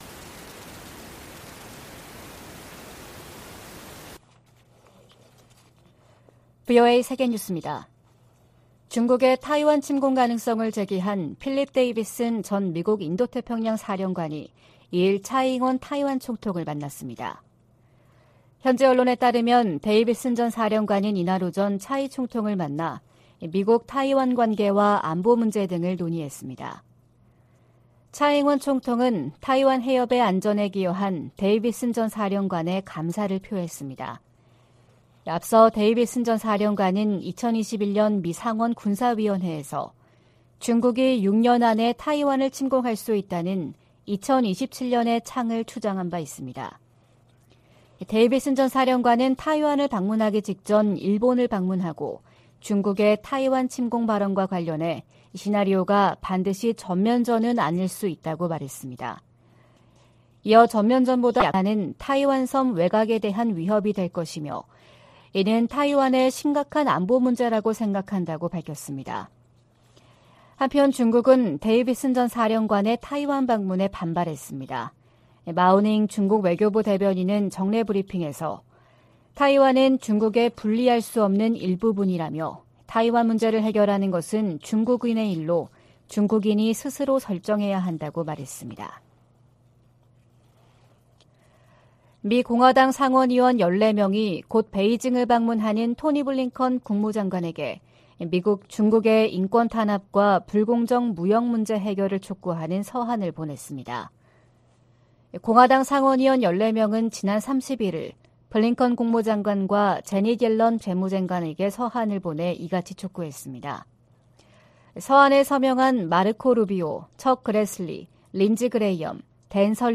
VOA 한국어 '출발 뉴스 쇼', 2023년 2월 3일 방송입니다. 한국 국방부는 미한 공군이 1일 서해 상공에서 미 전략자산 전개 하에 2023년 첫 연합공중훈련을 실시했다고 밝혔습니다.